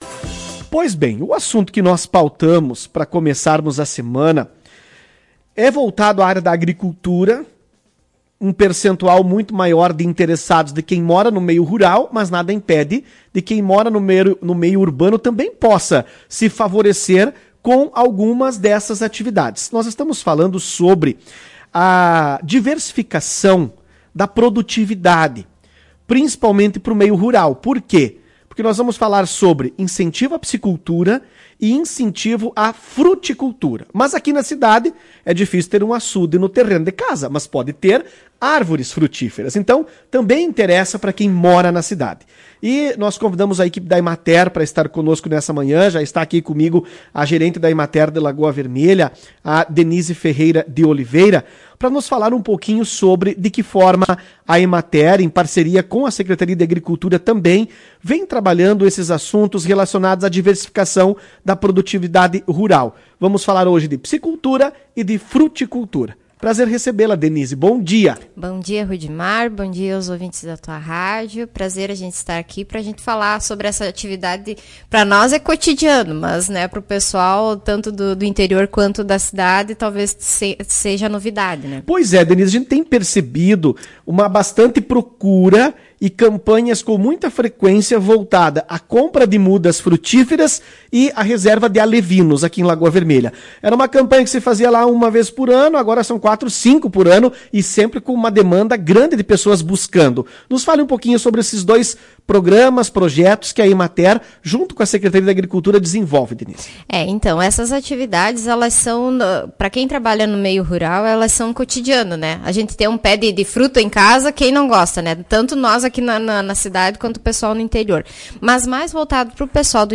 Incentivo à piscicultura e plantio de frutíferas é tema de entrevista com Emater